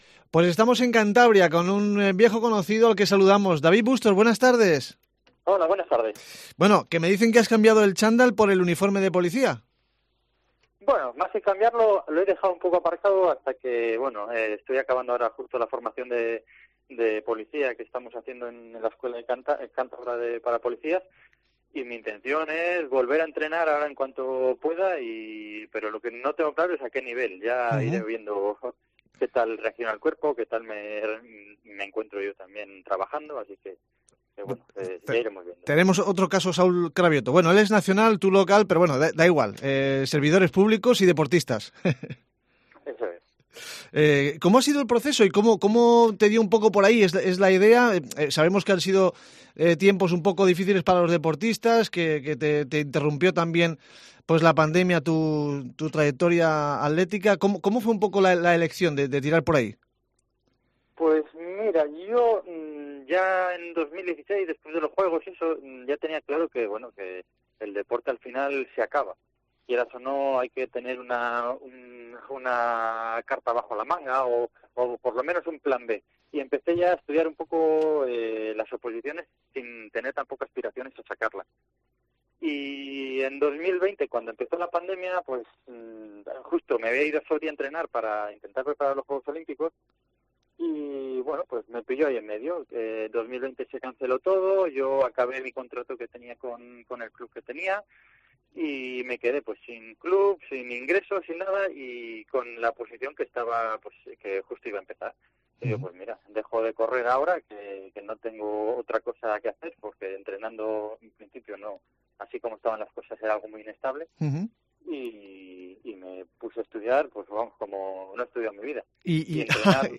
En esta entrevista nos cuenta cómo ha sido lo de cambiar el chándal por el uniforme, si bien matiza que va a continuar en el atletismo.